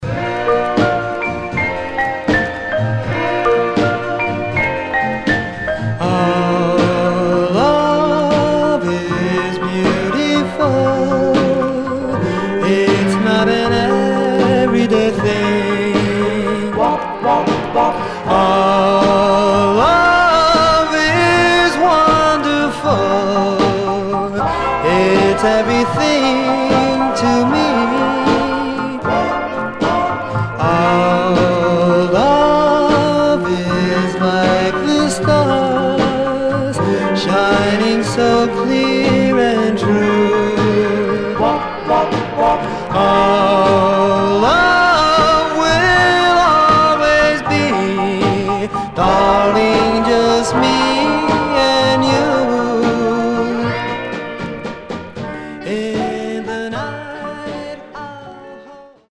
Condition Barely played Stereo/mono Mono